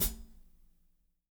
-16  HAT22-R.wav